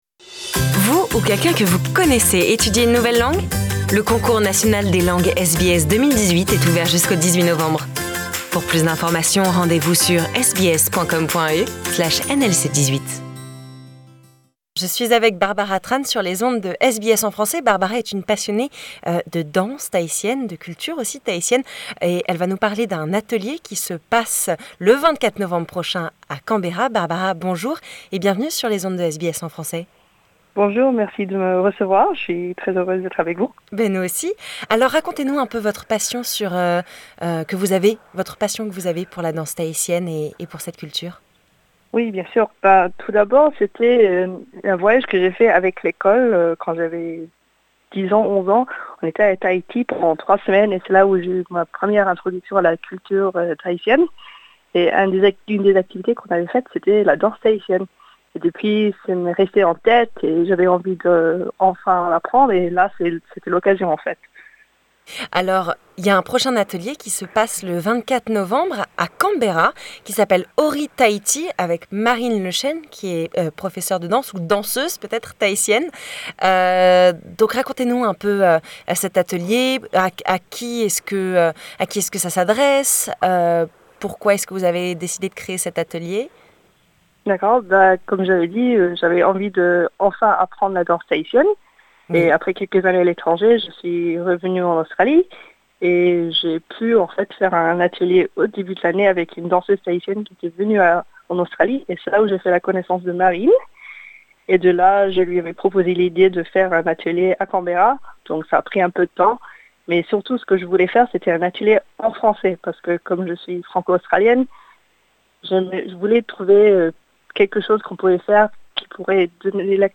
*cette interview date de novembre 2018.